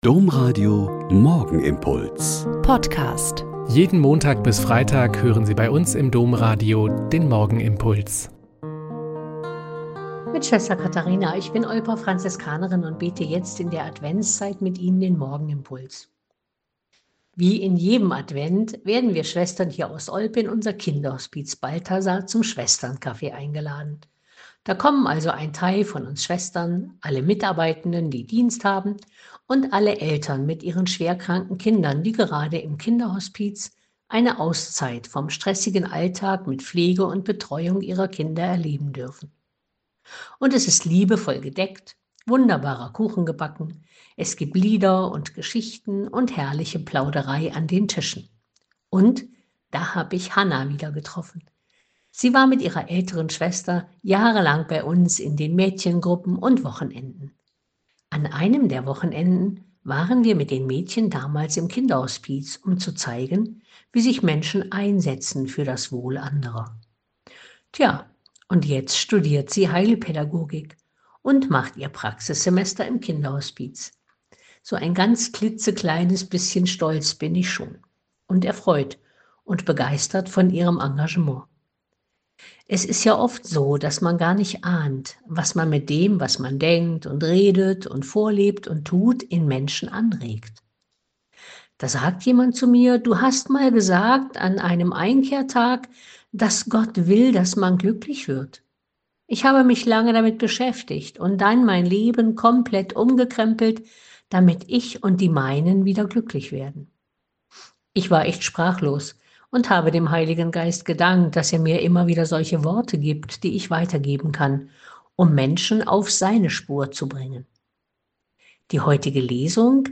Lk 1,5-25 - Gespräch